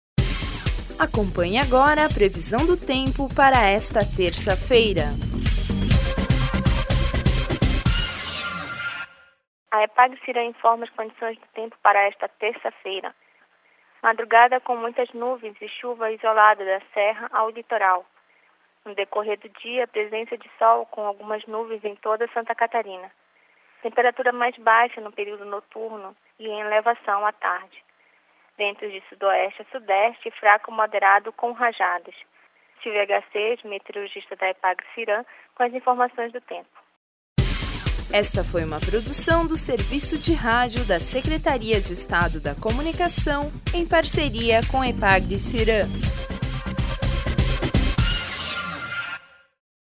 Previsão do tempo para terça-feira, 01/10/2013